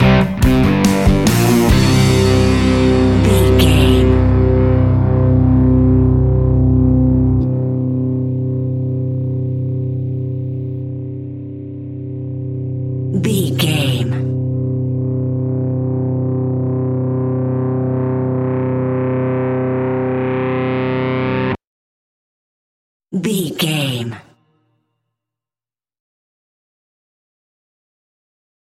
Aeolian/Minor
energetic
driving
heavy
aggressive
electric guitar
bass guitar
drums
hard rock
distortion
distorted guitars
hammond organ